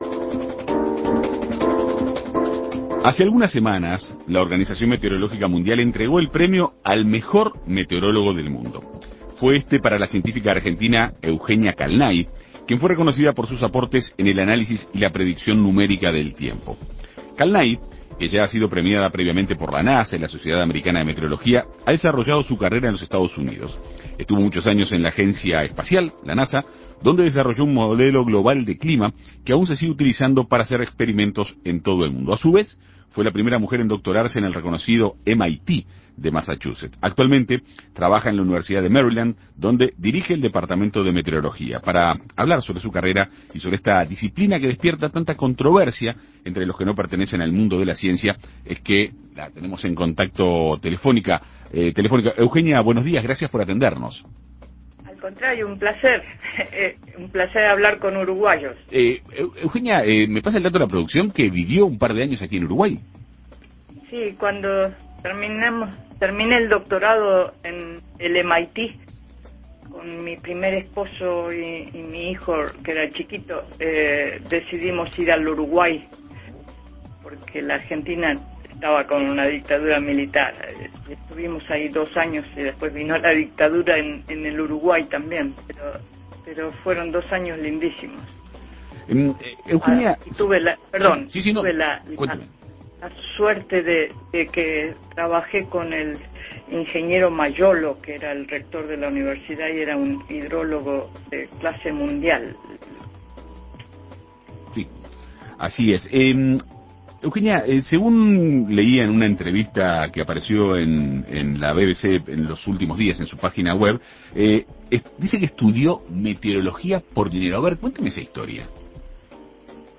En Perspectiva Segunda Mañana dialogó con ella para conocer detalles de su carrera y de la disciplina.